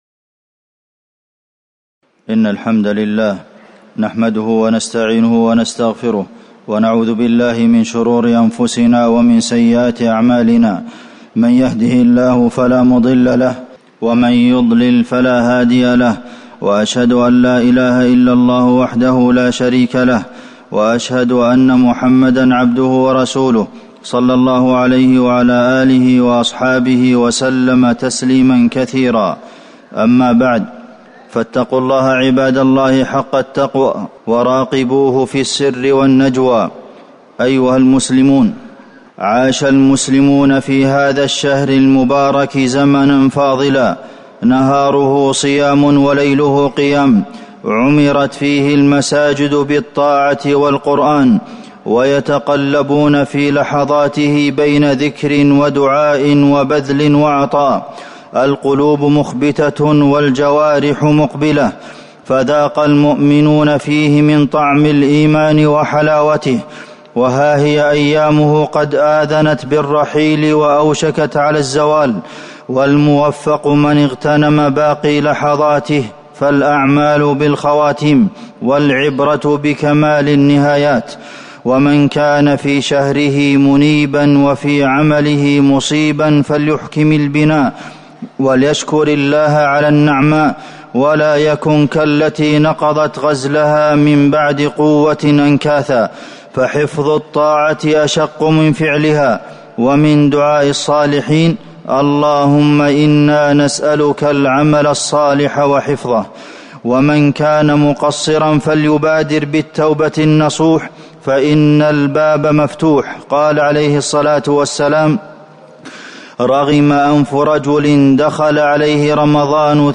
تاريخ النشر ٢٦ رمضان ١٤٤٠ هـ المكان: المسجد النبوي الشيخ: فضيلة الشيخ د. عبدالمحسن بن محمد القاسم فضيلة الشيخ د. عبدالمحسن بن محمد القاسم رحيل رمضان